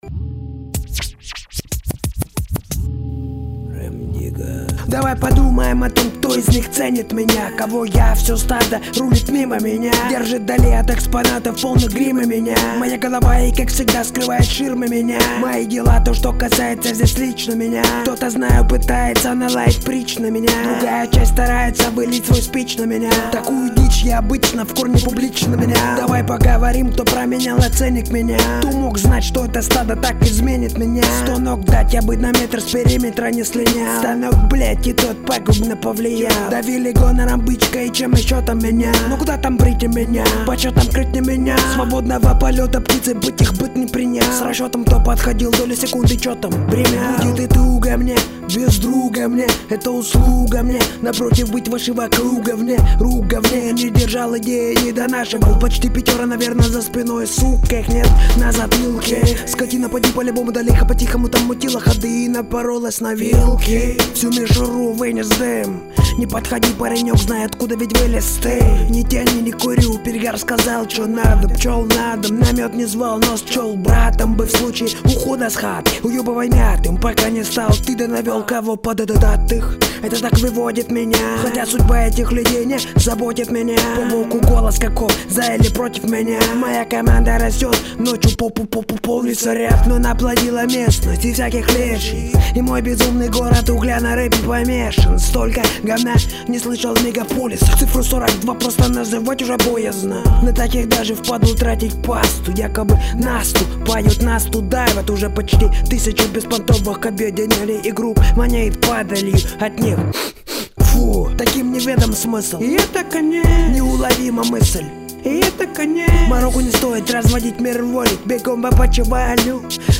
Категория: RAP, R&B